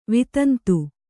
♪ vitantu